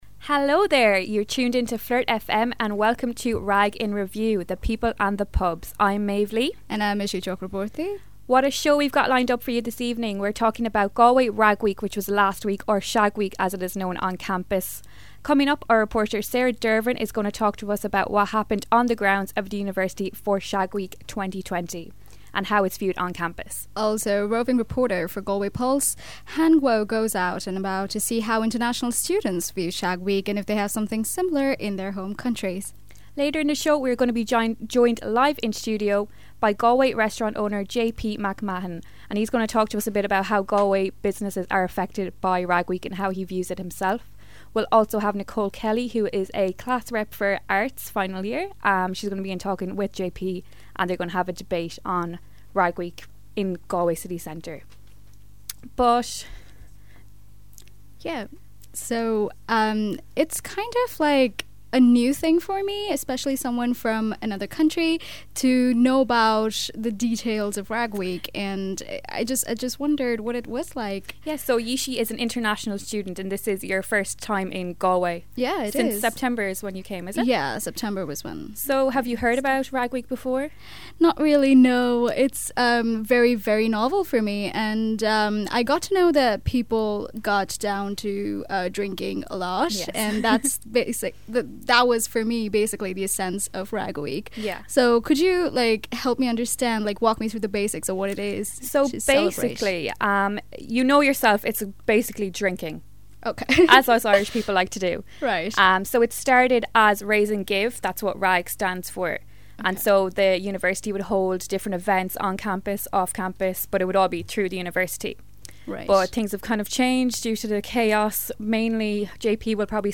Check out Rag in Review: The People and the Pubs – a live radio news programme reporting on Galway Rag Week which was recently broadcast on Flirt FM.